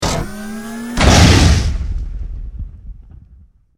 battlepunch.ogg